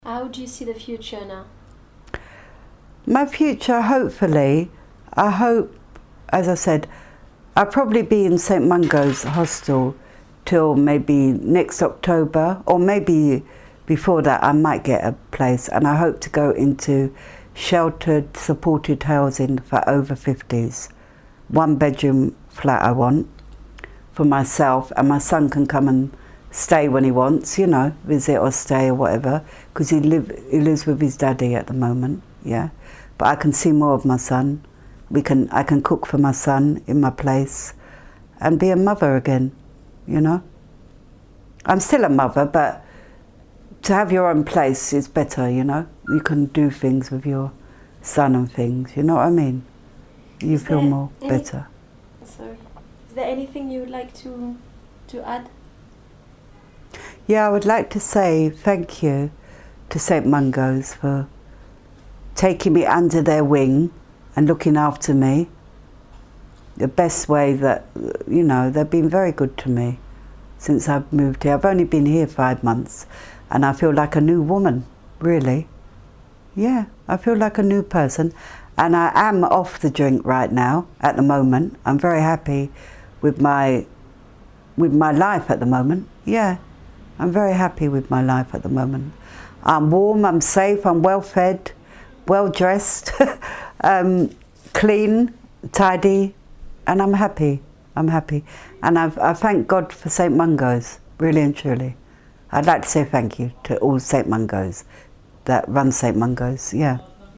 Some interviews contain strong language.